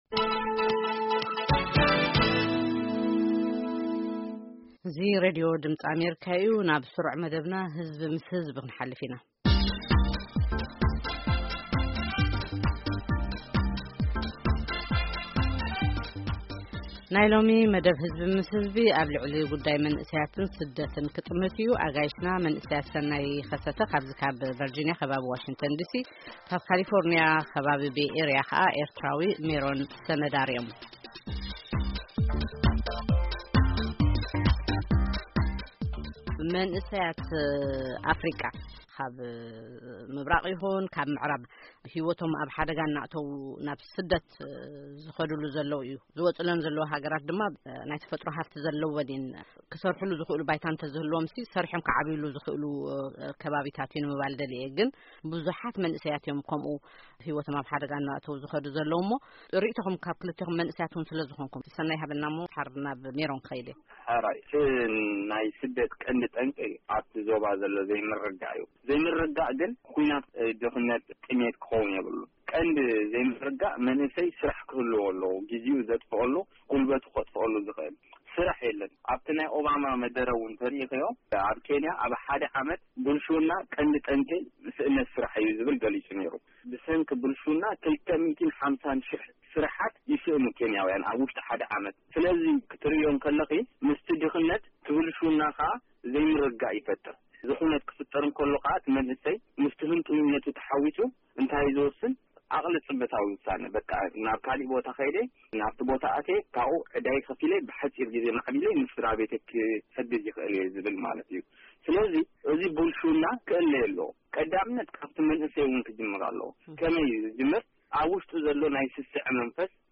Eritrean and Ethiopian Youth Discuss Migration